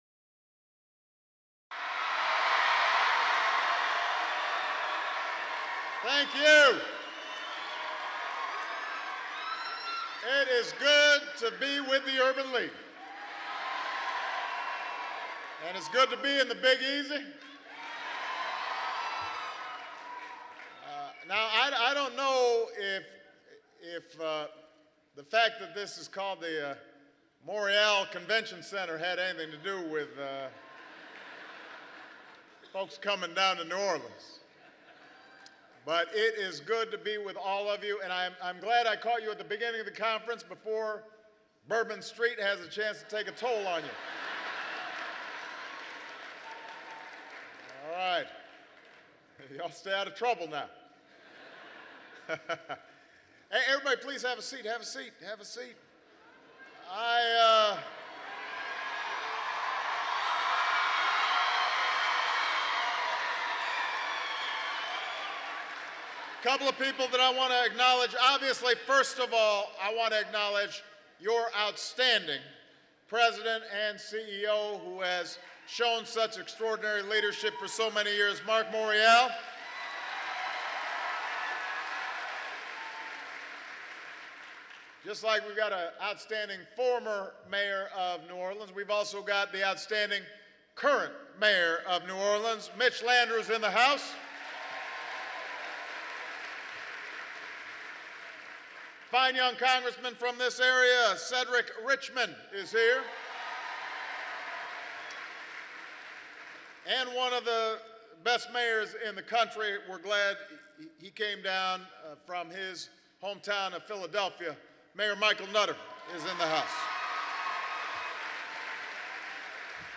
U.S. President Barack Obama speaks at the 2012 National Urban League Conference
President Obama addresses the National Urban League at their convention in New Orleans, LA. He recounts the struggle for equal rights and the reasons the Urban League was formed. Obama talks about equal opportunity, hard work, and achieving the American dream.